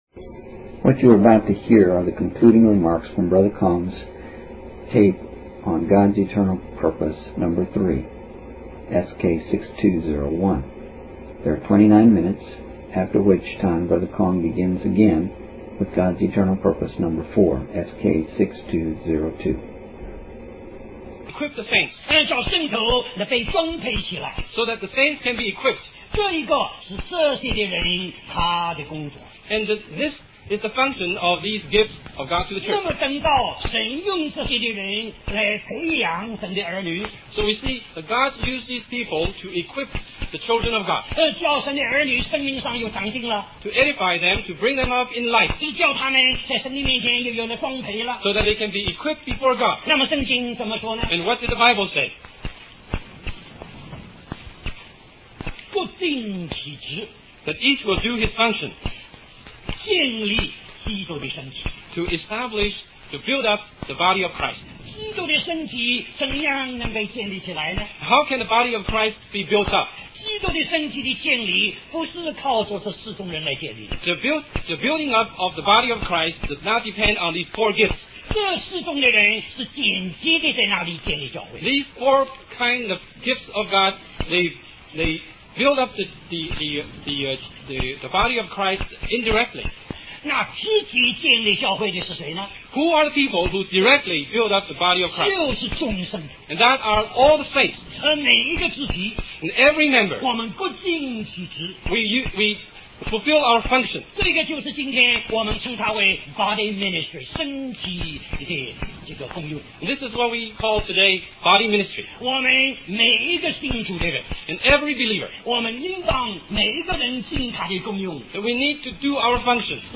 West Coast Christian Conference